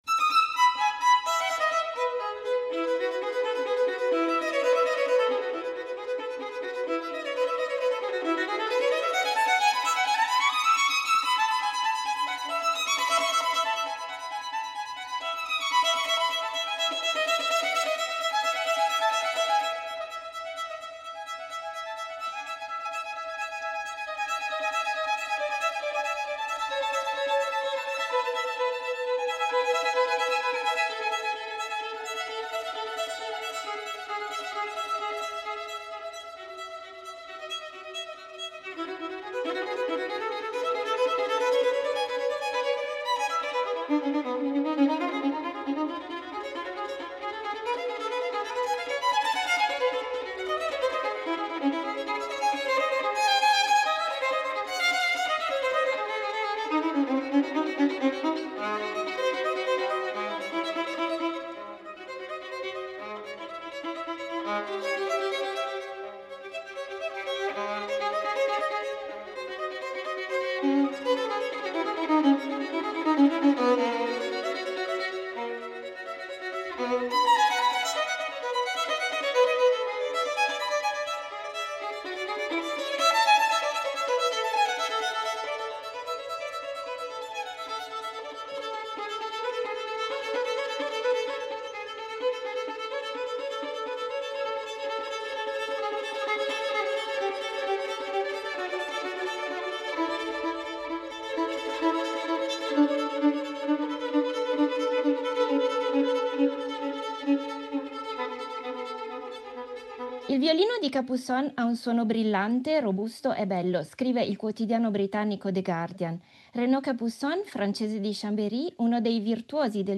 Incontro con Renaud Capuçon